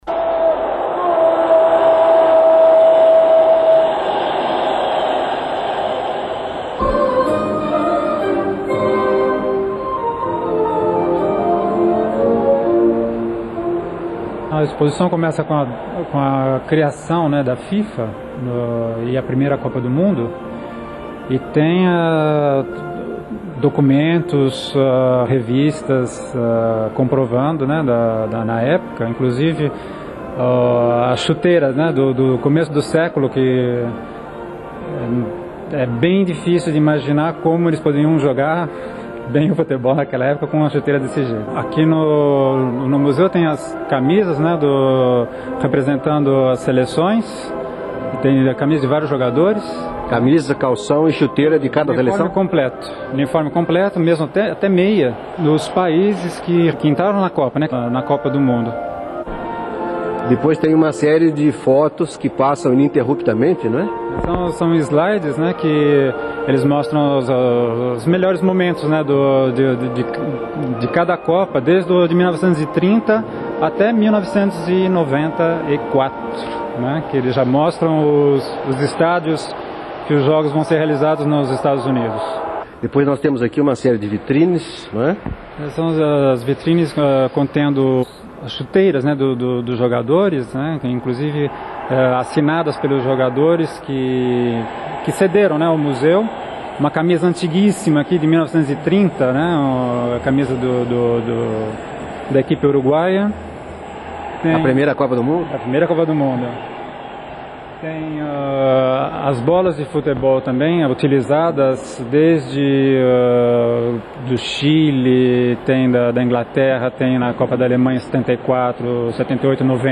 Uma exposição no Museu Olímpico, em Lausanne, mostra uma retrospectiva das Copas do Mundo desde 1930 no Uruguai até 1990 na Itália. Reportagem da Rádio Suíça Internacional, junho de 1994 (Duração: 4'59).